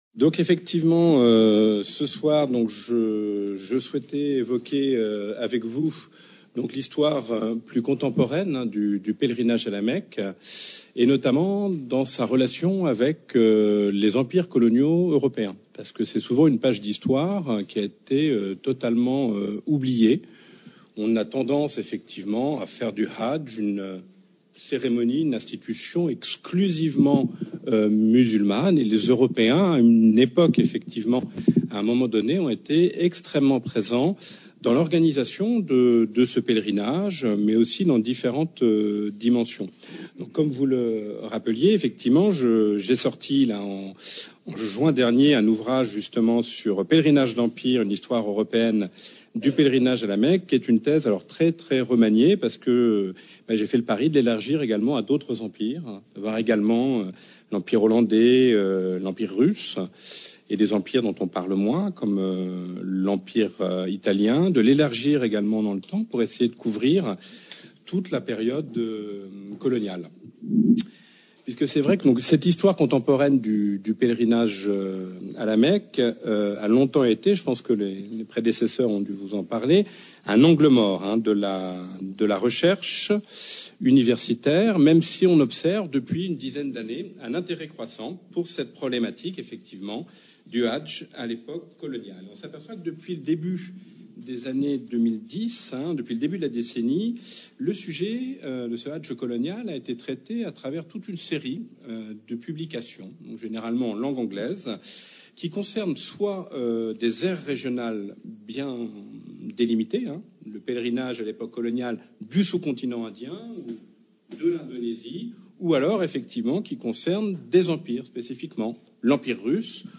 همایش